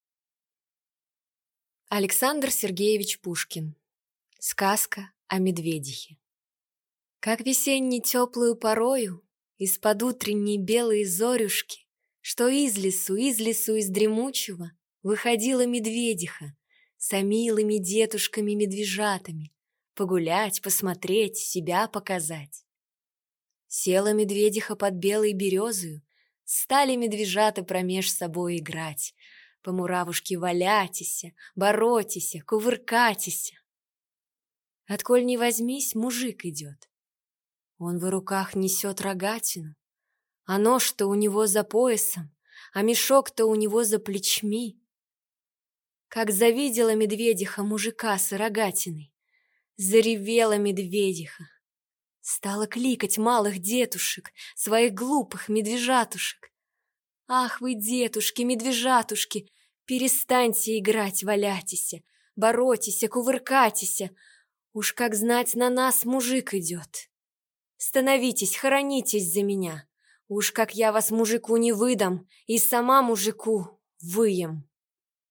Аудиокнига Сказка о медведихе | Библиотека аудиокниг